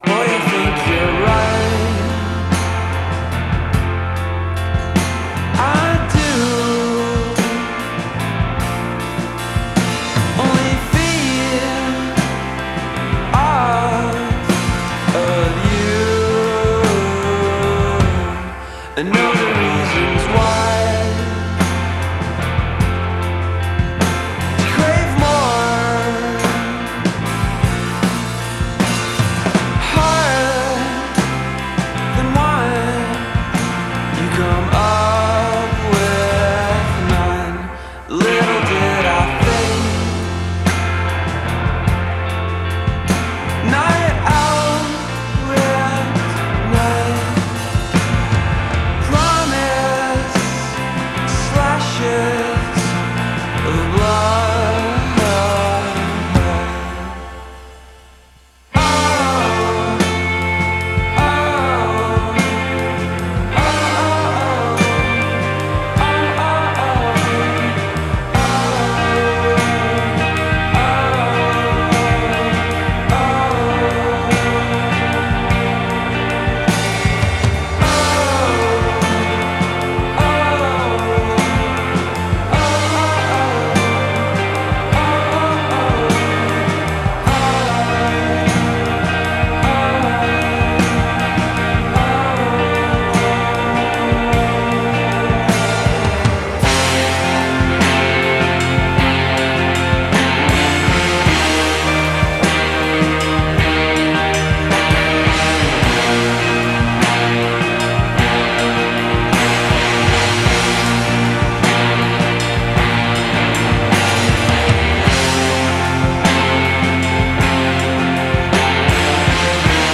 il sestetto bolognese